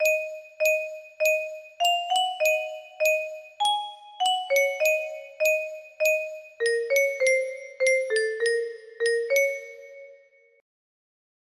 Yay! It looks like this melody can be played offline on a 30 note paper strip music box!